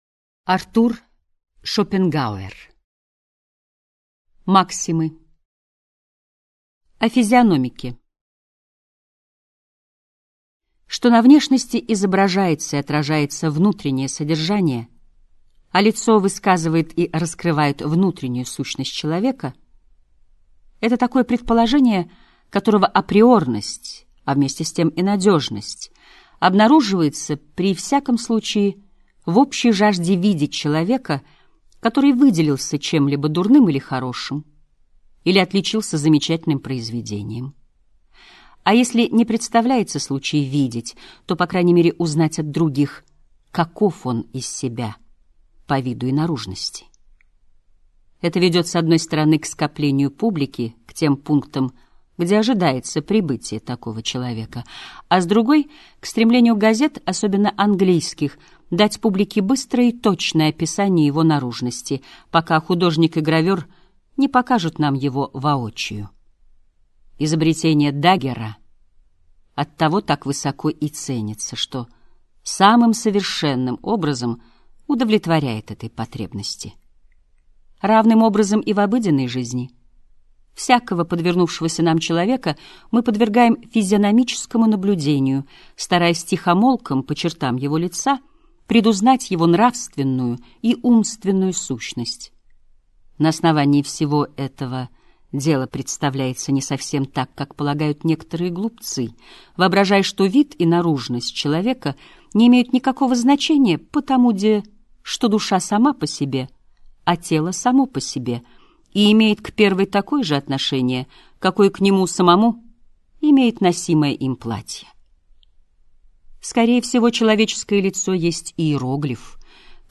Аудиокнига Максимы. Идеи этики | Библиотека аудиокниг